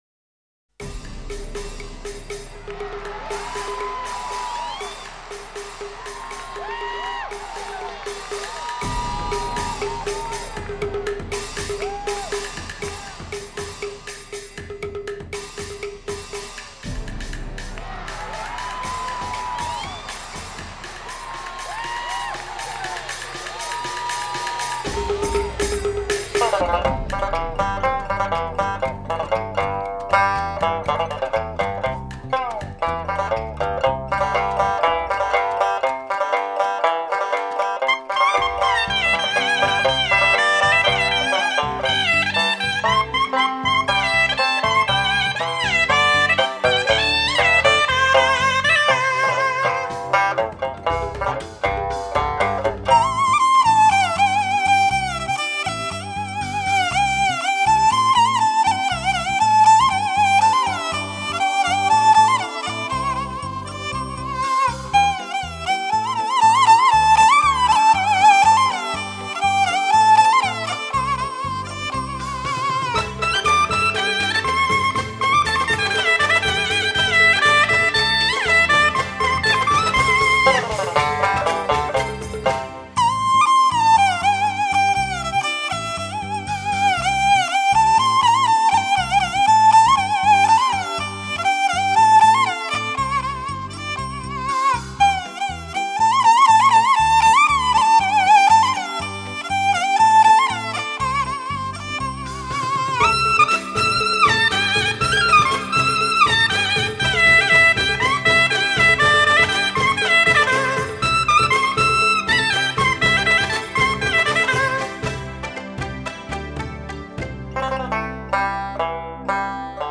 东北沃野万里、物产丰盛，这块土地上蘊育的民乐，也显得丰腴、乐观、健朗、泼辣。
发烧音效，堪称民乐天碟。
东北民歌